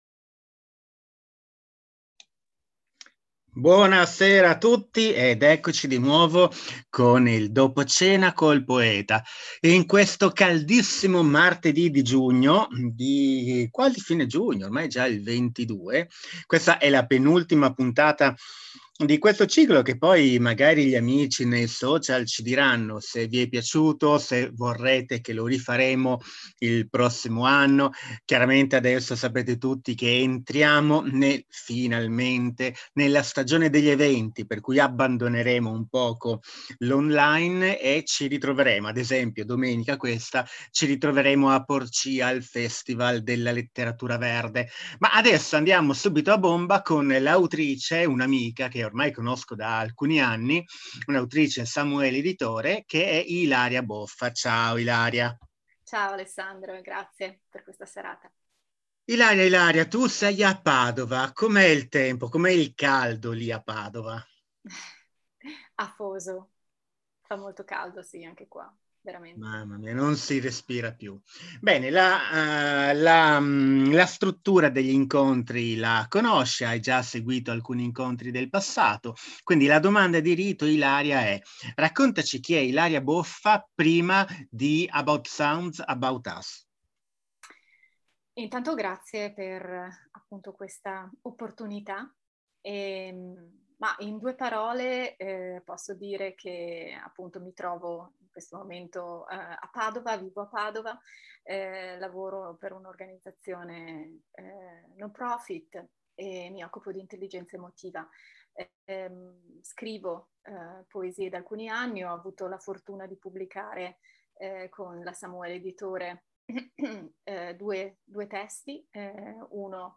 Piccole dirette in solo streaming audio coi poeti della Samuele Editore